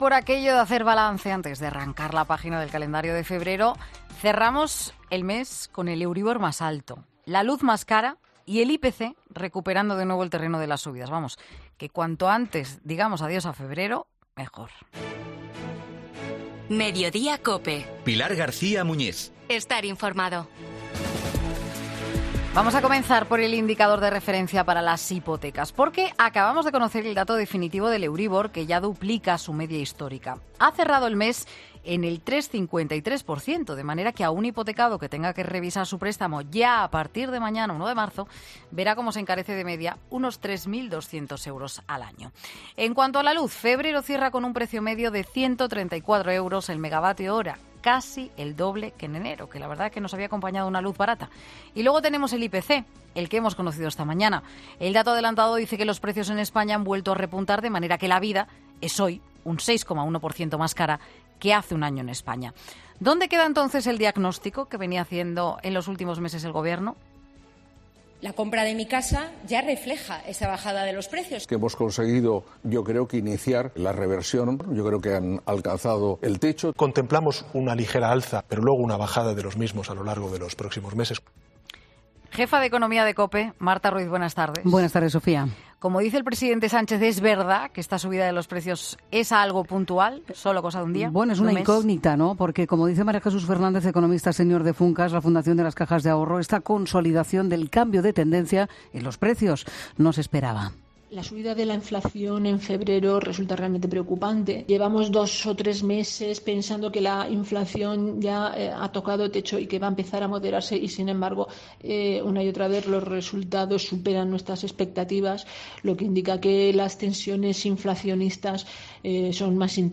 Análisis datos económicos en 'Mediodía': El euríbor supera en febrero el 3,53 % y duplica su media histórica